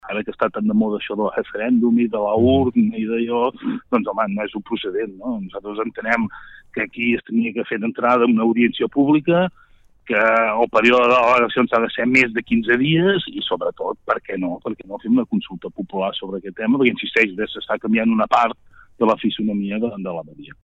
El regidor de Guíxols des del carrer, de l’entorn dels comuns, Jordi Lloveras, ha assegurat a una entrevista a Ràdio Capital que els seu grup tindrà “una postura activa” durant el referèndum de l’1 d’octubre.